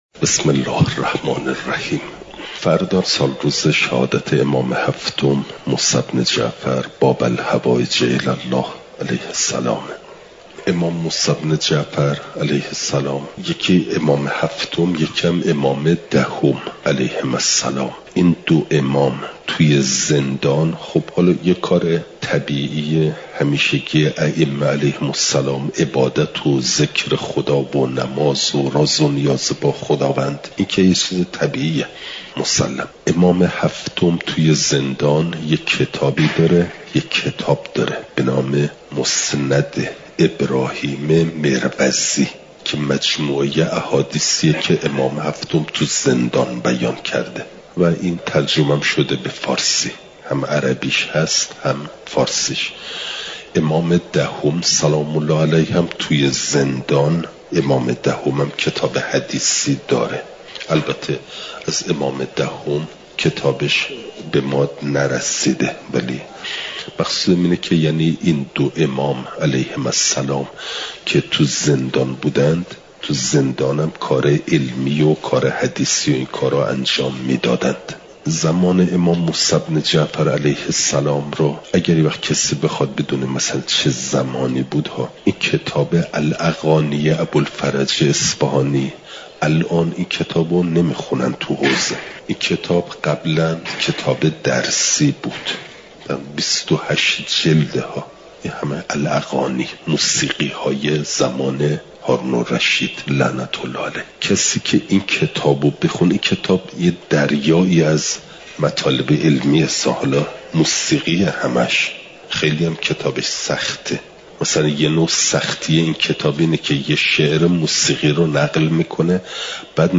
شنبه ۶ بهمن‌ماه ۱۴۰۳، حرم مطهر حضرت معصومه سلام ﷲ علیها